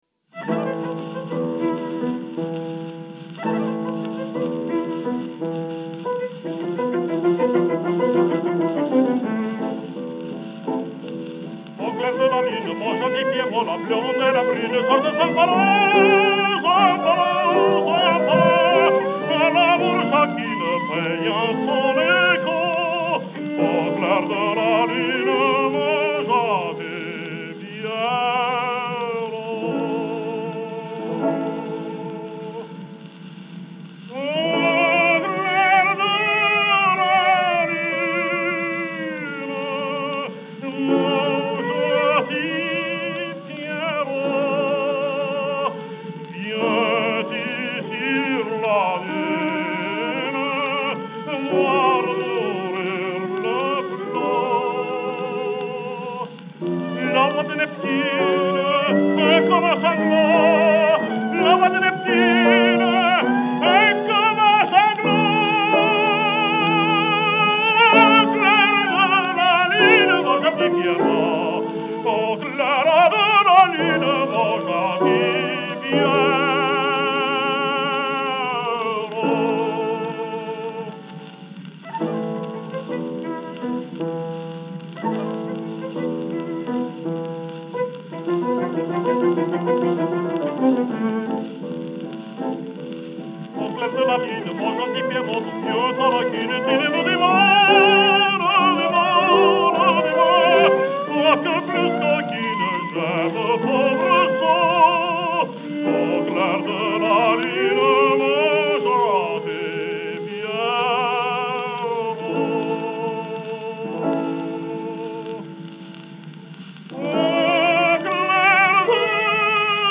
Жанр: Vocal
violin
piano